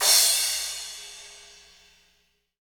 Index of /90_sSampleCDs/Sound & Vision - Gigapack I CD 1 (Roland)/CYM_K-CRASH st/CYM_K-Crash st 2
CYM CRA360BR.wav